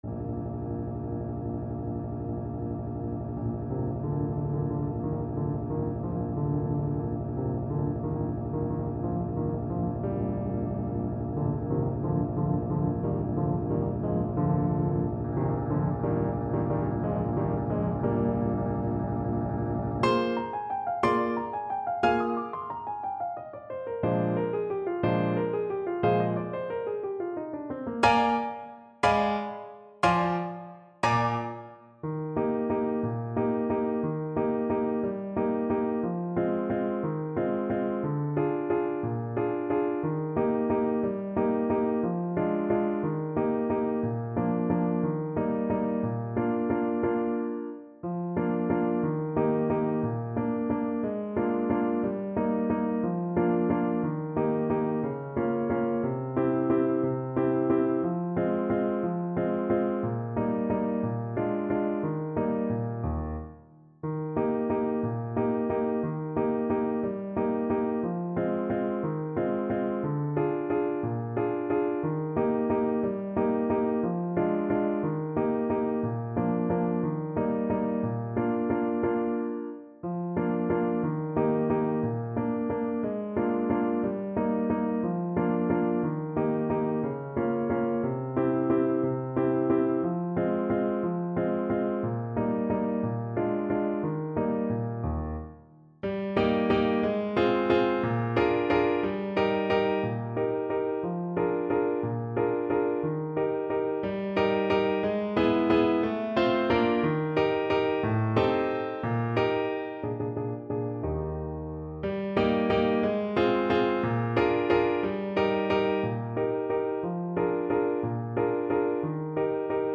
Trumpet version
3/4 (View more 3/4 Music)
F4-G6
Tempo di Valse .=c.60
Classical (View more Classical Trumpet Music)